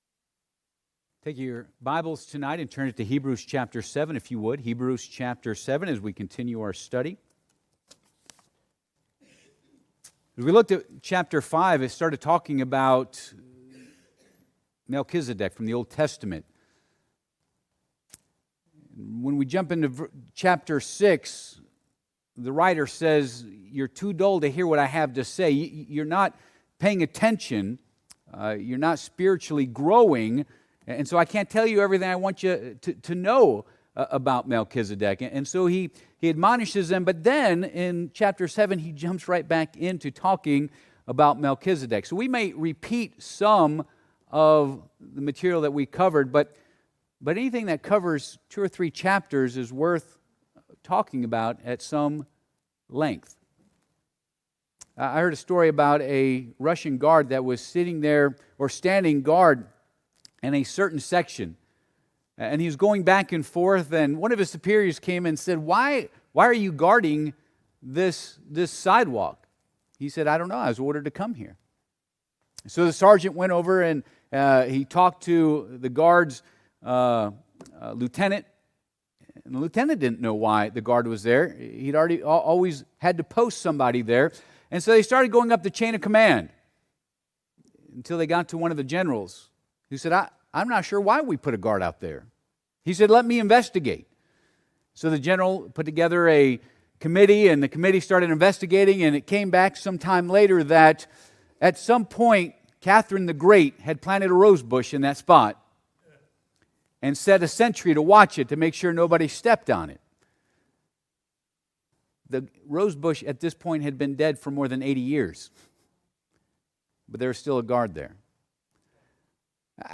Passage: Hebrews 7 Service Type: Midweek Service « When Your World Is Turned Upside Down The Kings of Israel and Judah